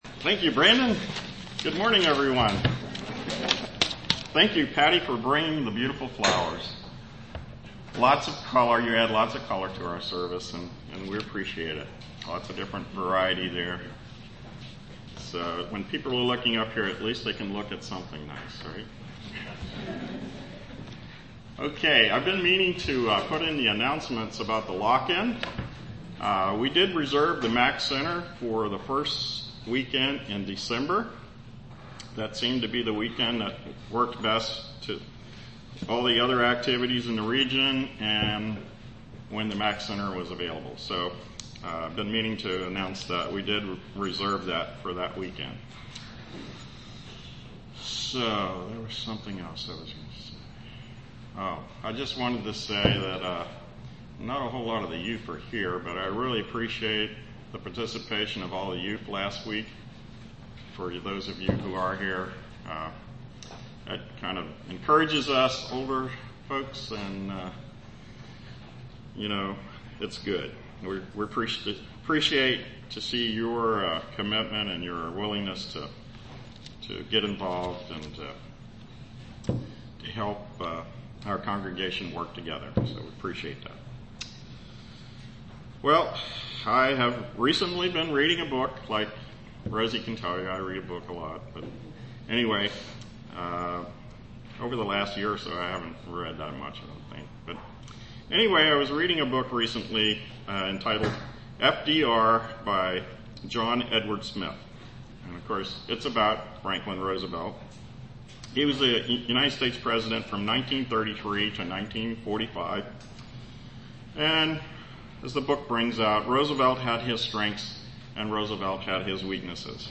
UCG Sermon righteousness Studying the bible?